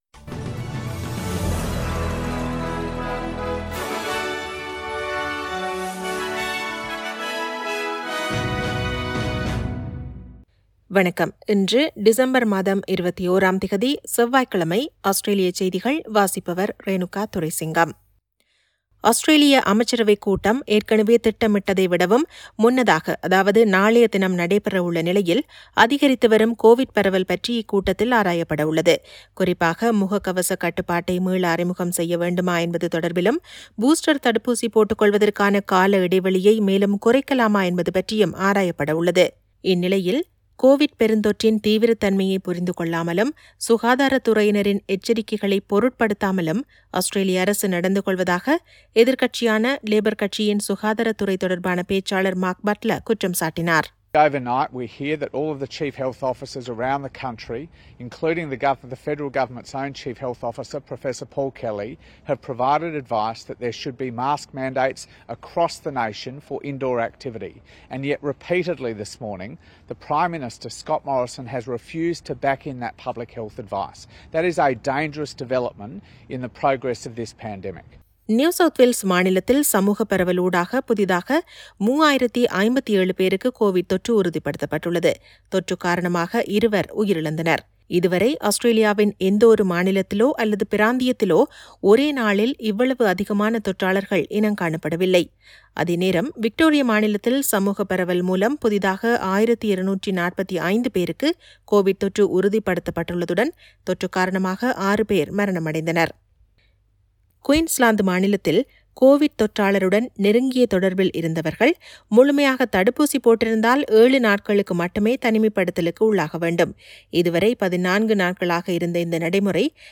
Australian news bulletin for Tuesday 21 December 2021.